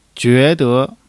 jue2-de.mp3